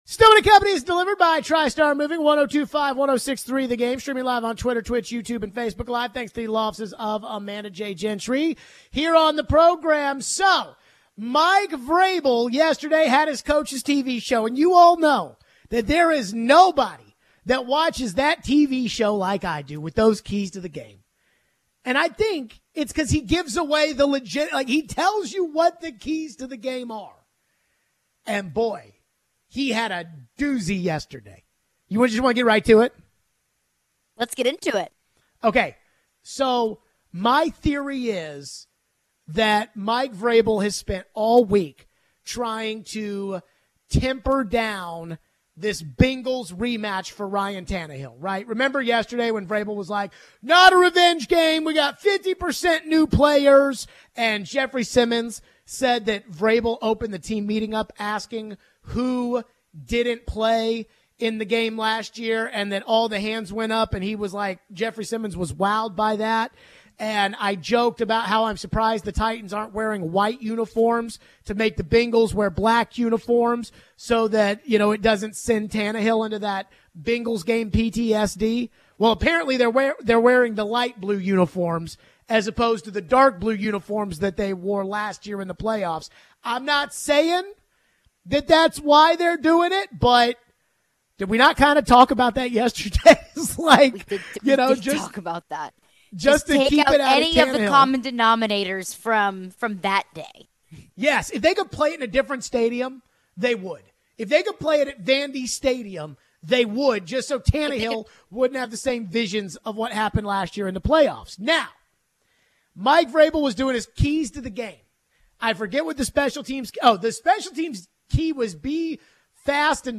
Mike Vrabel talked about his key to the game on offense vs CIN this week. What do we think of that and do we think the team is worried about putting too much on Tannehill this week? We take your phones. We talk some about the Vols and recent CFP rankings.